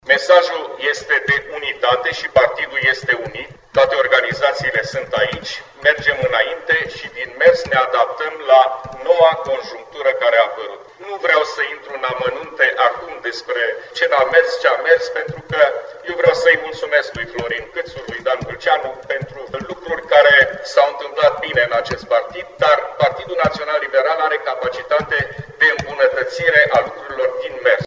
În cadrul unei conferințe de presă, FLUTUR a mulțumit pentru activitate fostului președinte, dar a adăugat că “partidul trebuie să se adapteze și să meargă înainte”.